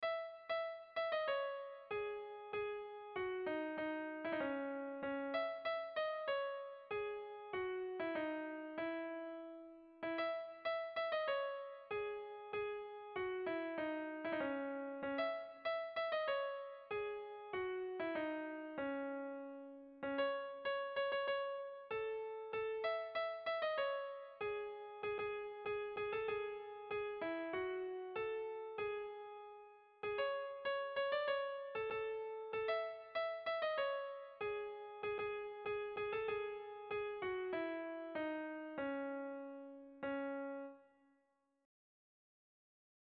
Tragikoa
Zortziko handia (hg) / Lau puntuko handia (ip)
AAB1B2